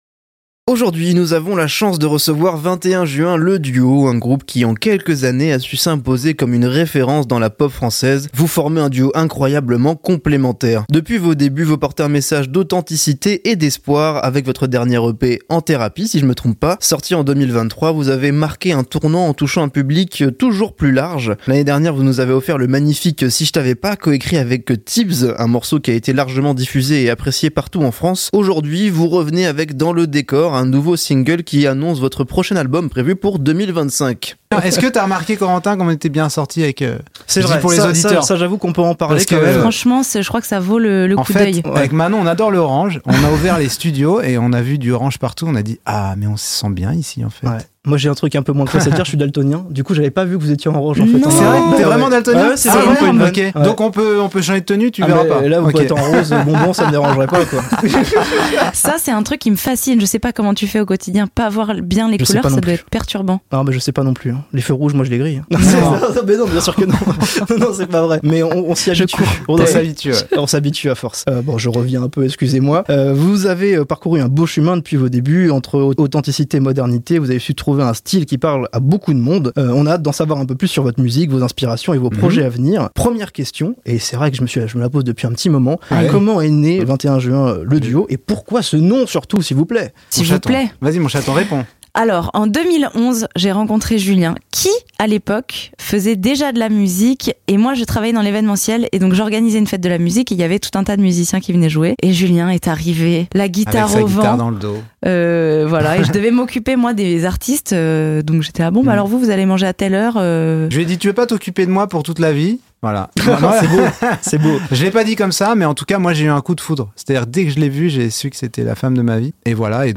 21 Juin Le Duo – Interview + Le Live